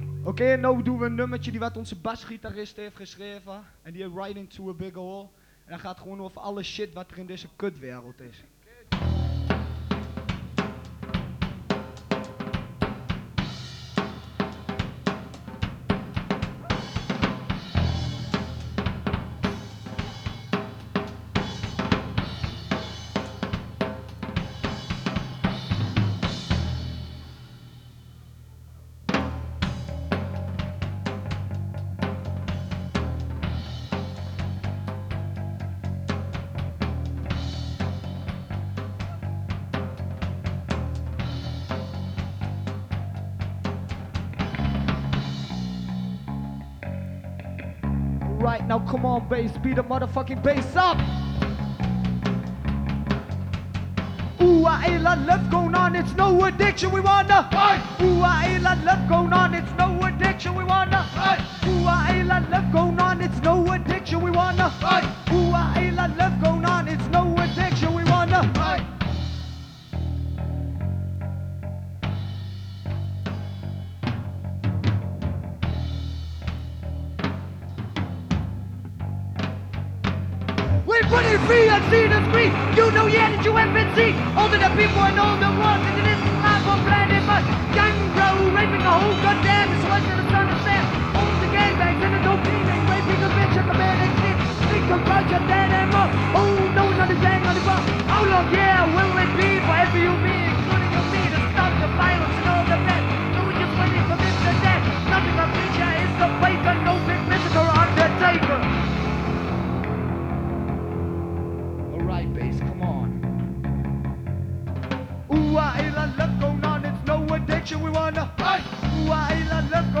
Live in Atak Enschede